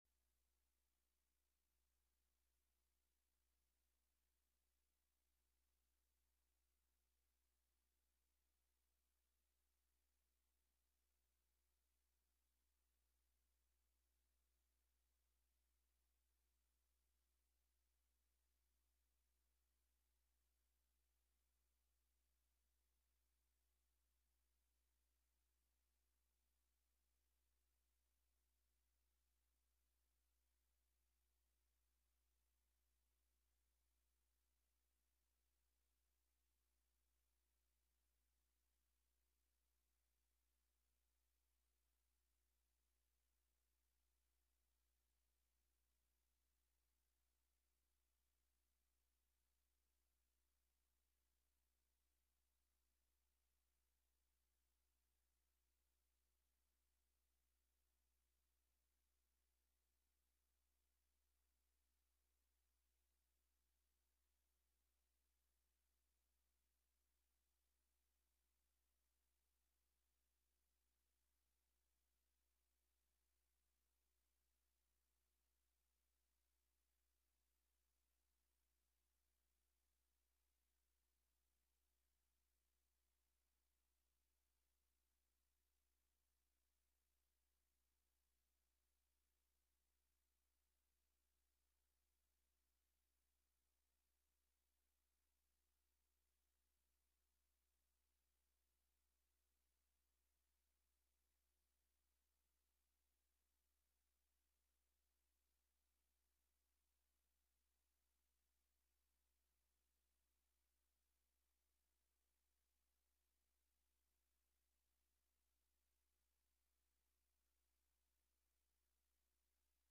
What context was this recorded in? Prime Jive: Monday Afternoon Show- Live from Housatonic, MA (Audio)